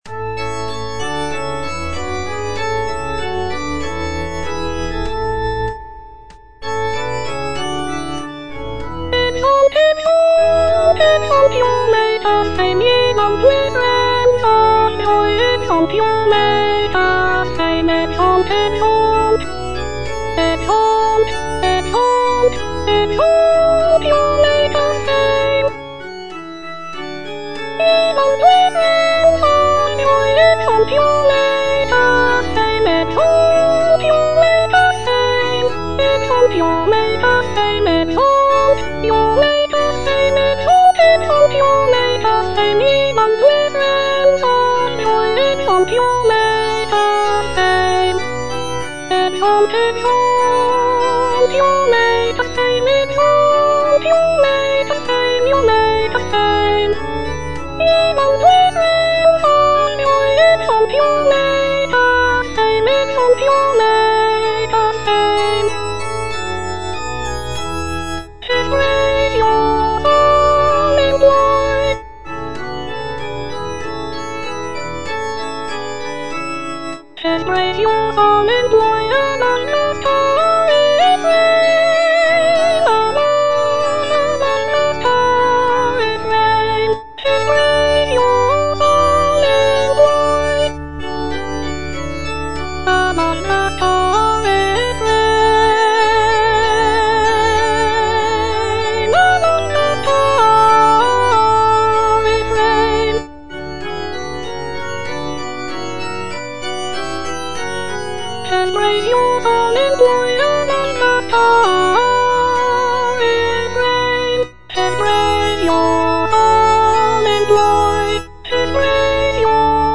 G.F. HÄNDEL - O PRAISE THE LORD WITH ONE CONSENT - CHANDOS ANTHEM NO.9 HWV254 (A = 415 Hz) Ye boundless realms of joy - Soprano (Voice with metronome) Ads stop: auto-stop